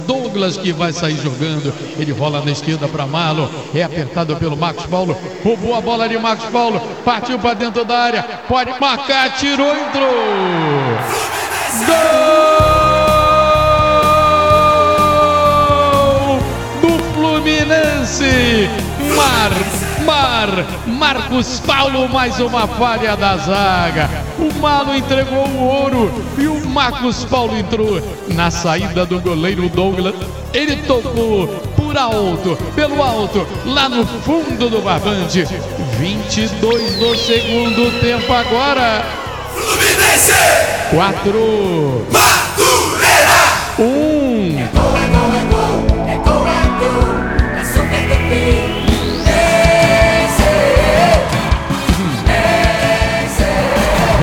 Ouça os gols da goleada do Fluminense sobre o Madureira, na voz de José Carlos Araújo